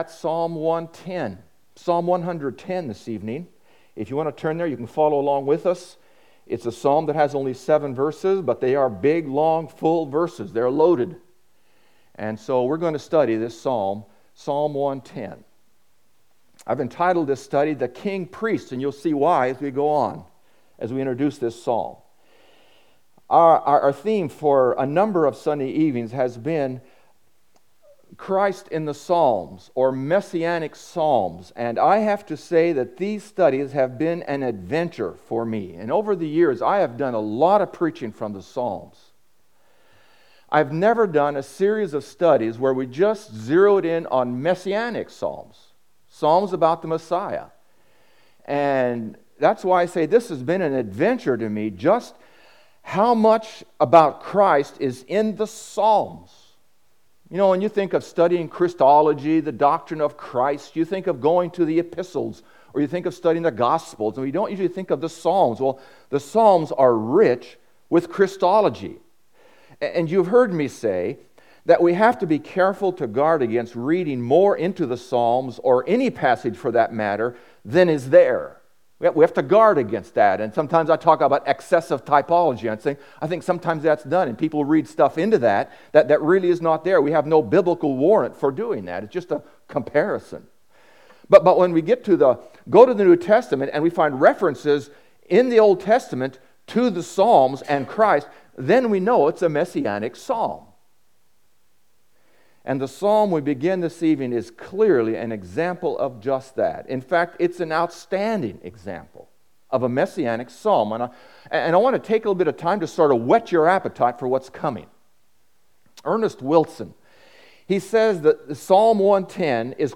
Part 1 Preacher